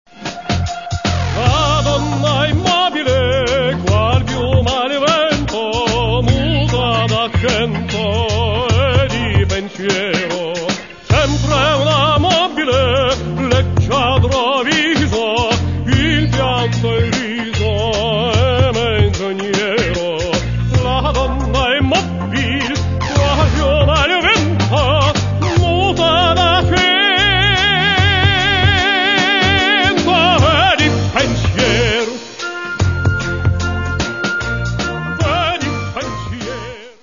Catalogue -> Classical -> Opera and Vocal